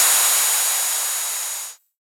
RDM_TapeA_SY1-OpHat.wav